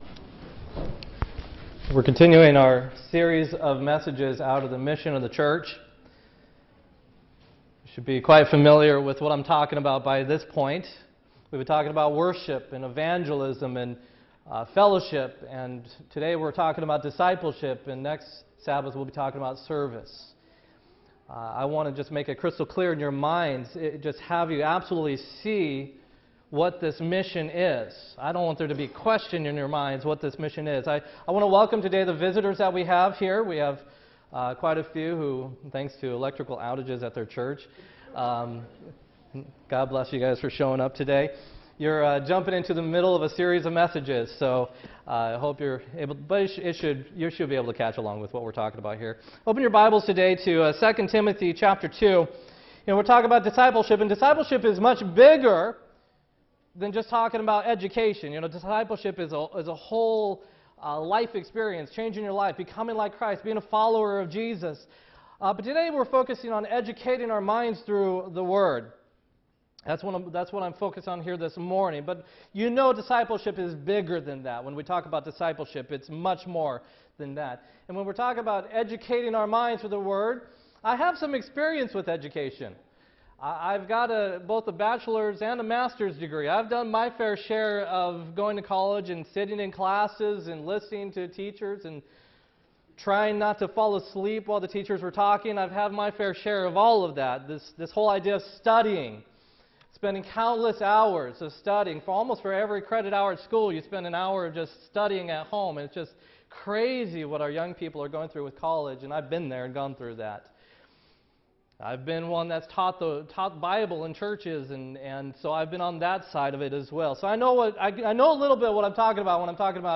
8-27-16 sermon